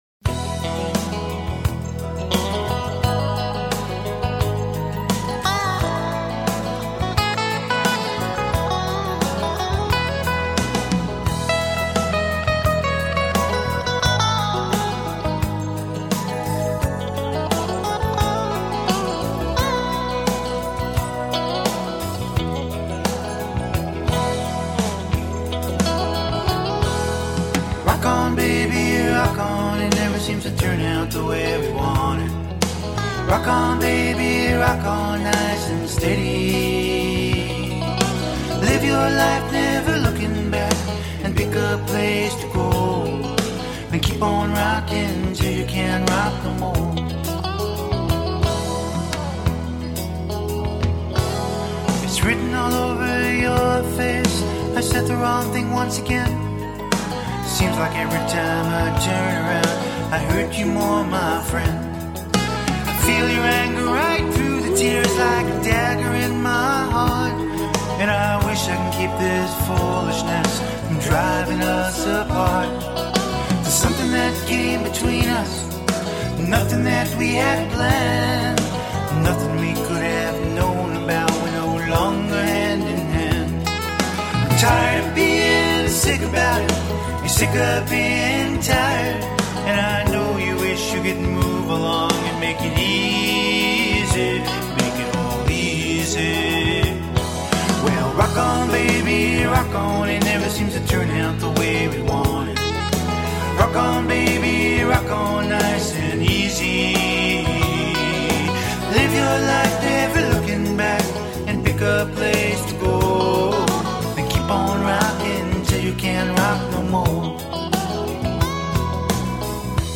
pop rock group